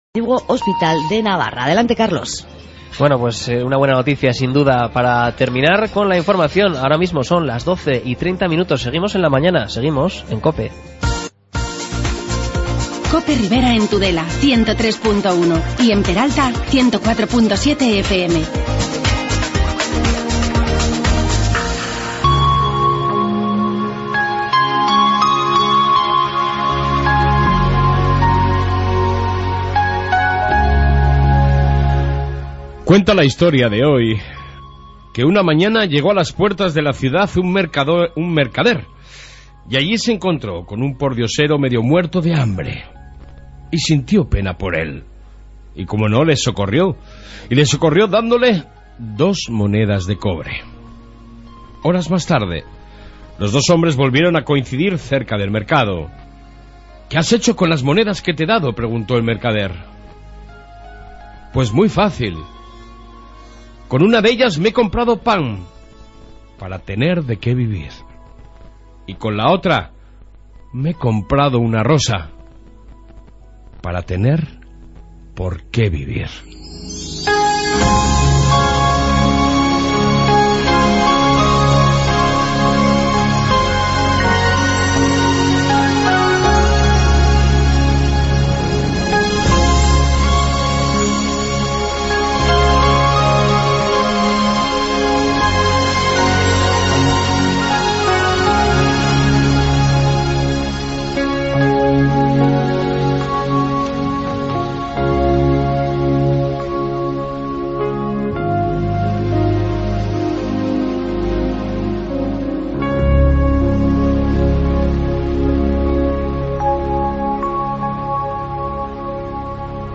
AUDIO: Reflexión + Entrevista